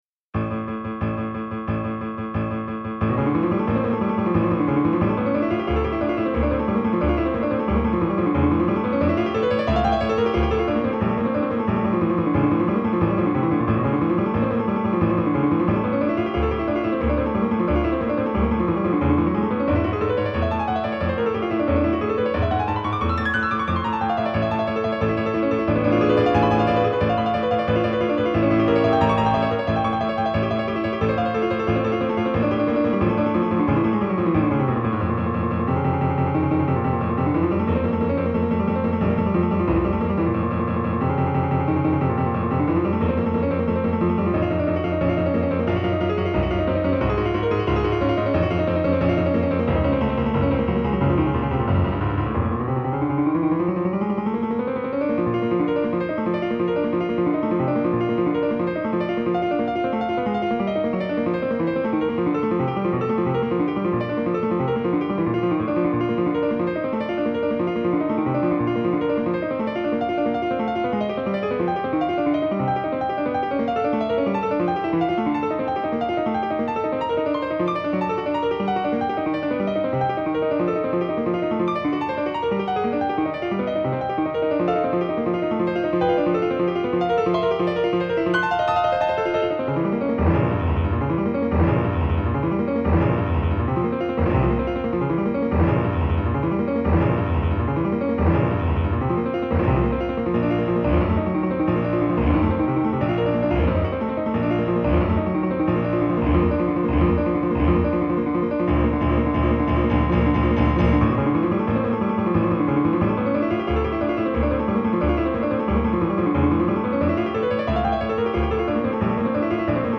クラシックピアノから私のお気に入りをmidiで打ち込んでみました。
今回の打ち込みでは無理なく演奏可能、というコンセプトで速度をかなり落としています。実際は4分の2拍子で1分間に112小節という恐ろしく速い曲。